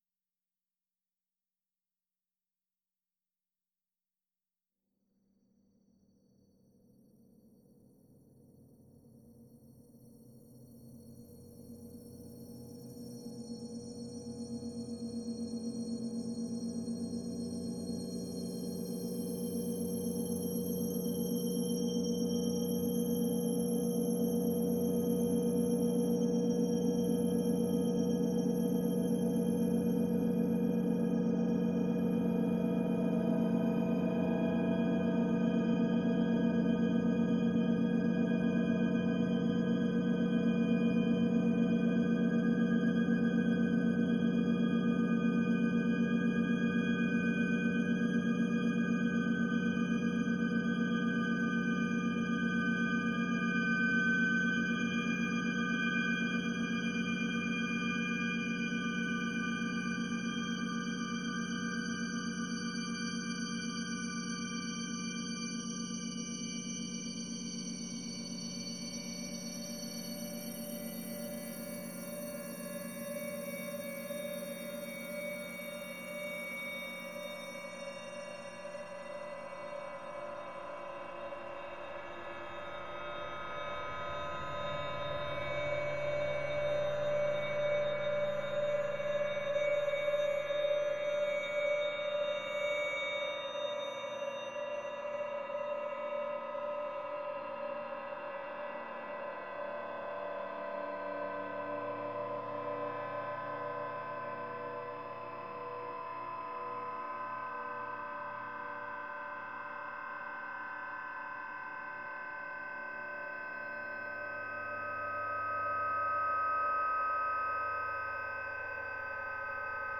experimental music